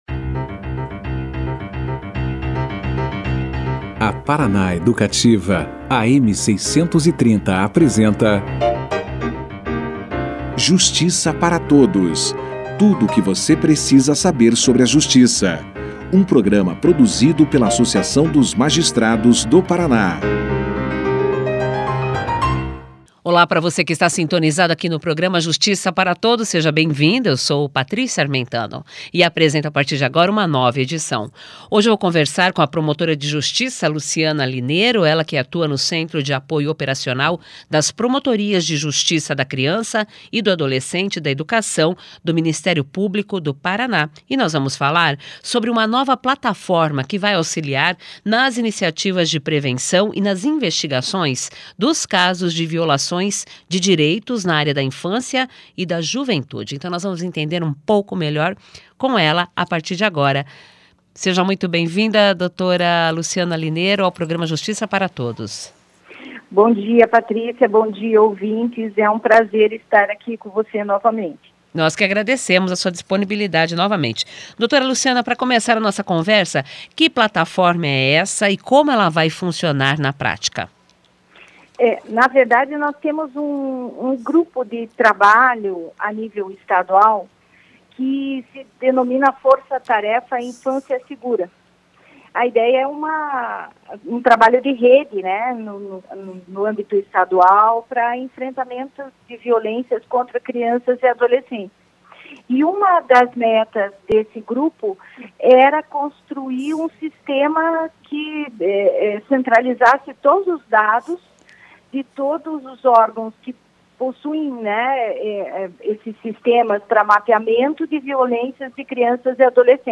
>>Clique Aqui e Confira a Entrevista na Integra<<
A Promotora de Justiça Luciana Linero, que atua no Centro de Apoio Operacional das Promotorias de Justiça da Criança e do Adolescente e da Educação do Ministério Público do Estado, falou sobre a importância do novo sistema no Programa Justiça Para Todos. Segundo ela, as ocorrências criminais envolvendo crianças e adolescentes tiveram uma concentração ainda maior no ambiente doméstico familiar nesse um ano de pandemia da Covid-19.